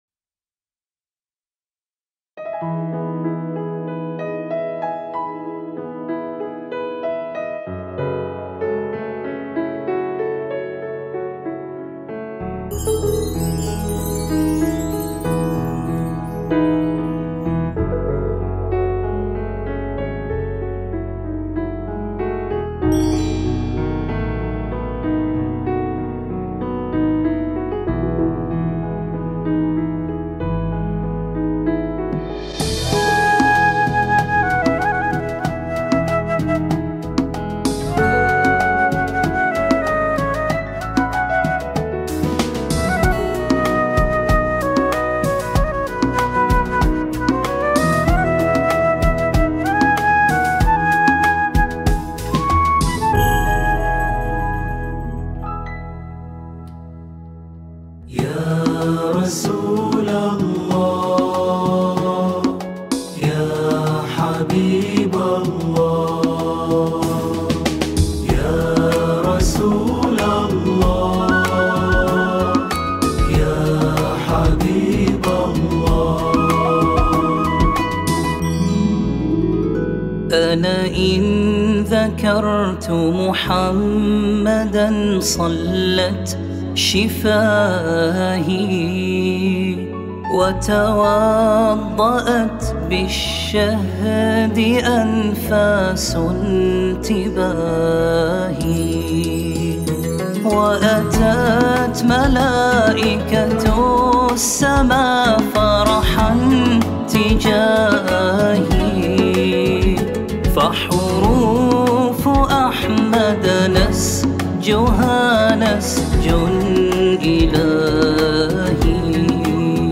الكورال: